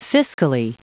Prononciation du mot fiscally en anglais (fichier audio)